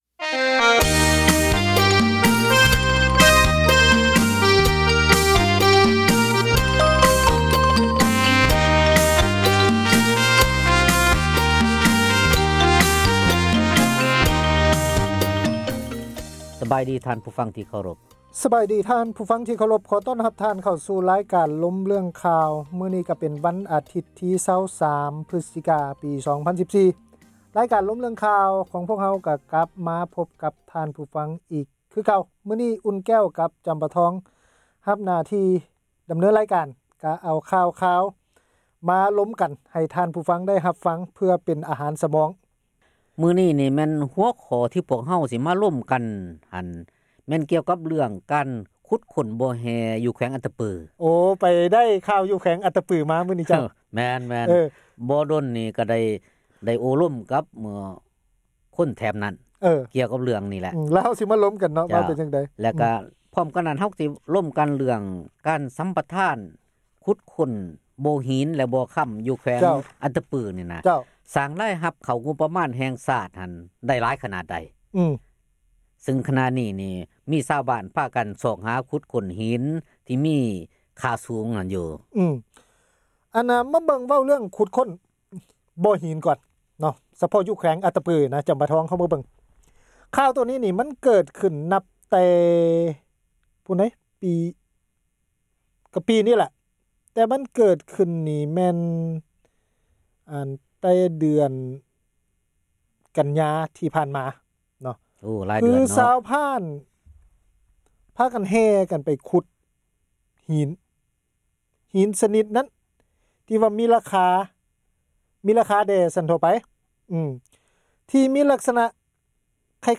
ການສົນທະນາ